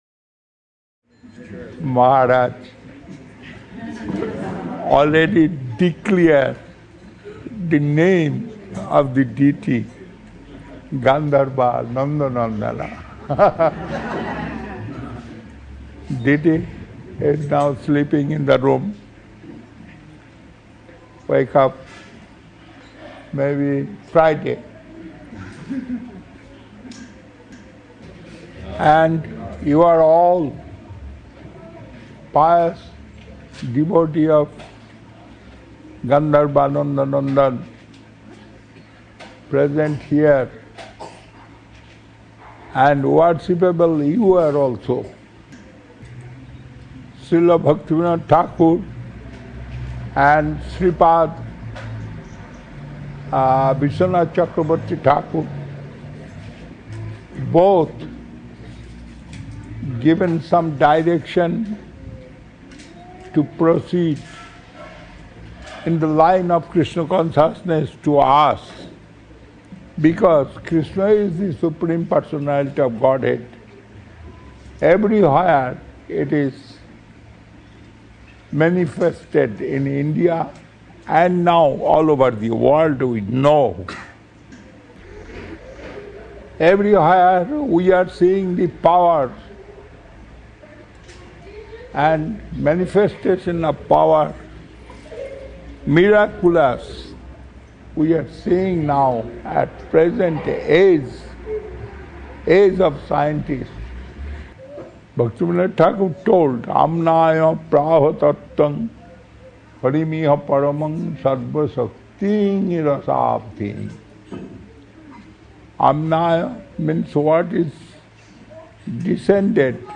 Place: SCSMath Veracruz